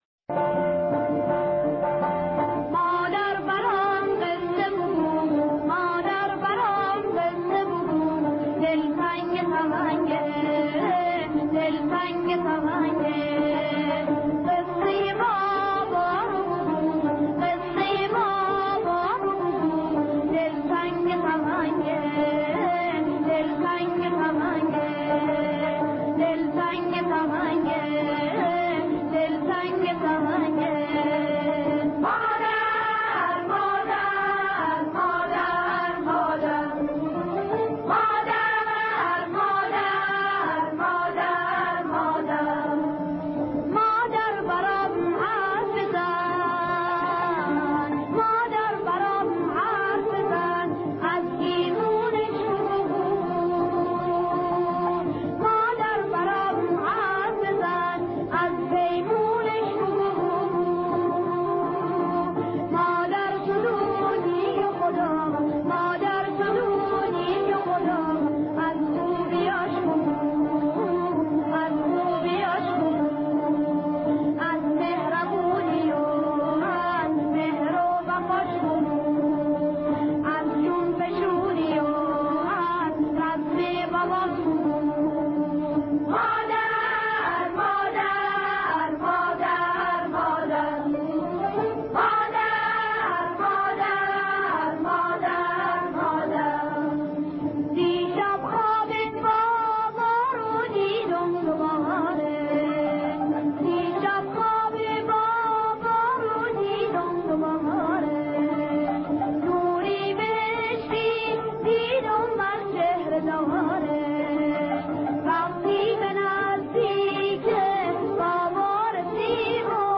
آهنگ قدیمی و نوستالژیک